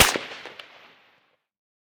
heav_crack_03.ogg